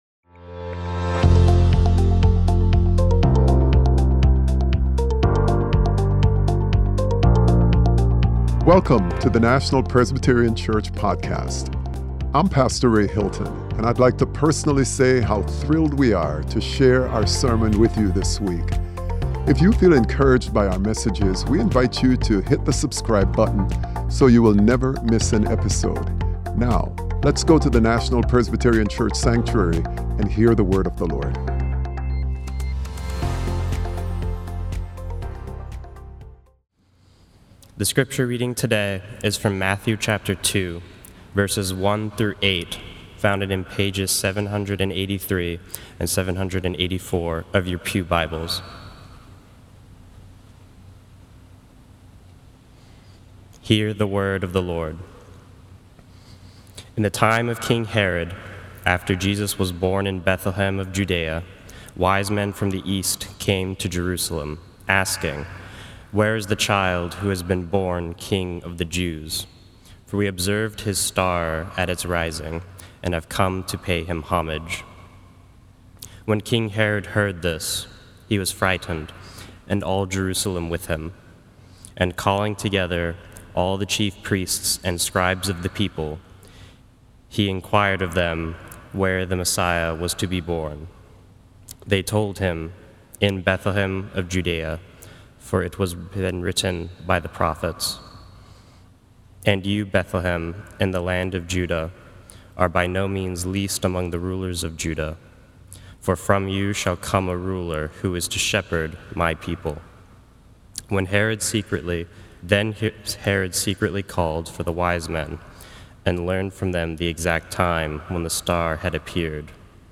Sermon - Herod: Poisoned by Fear - National Presbyterian Church